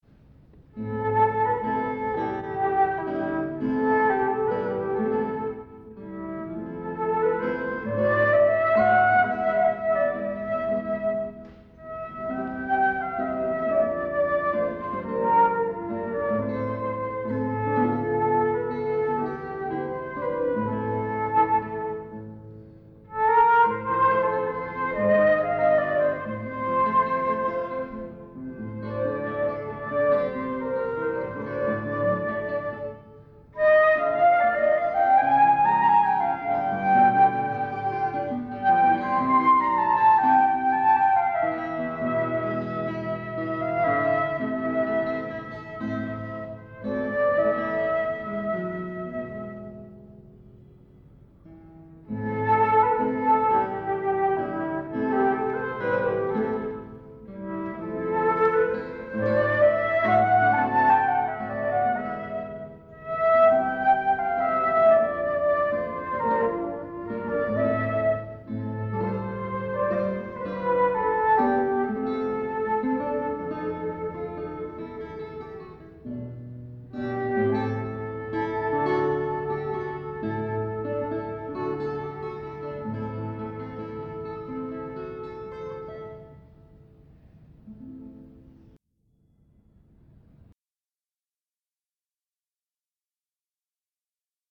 Duo flauto e chitarra
Circolo Eridano, Torino 2 Aprile 1993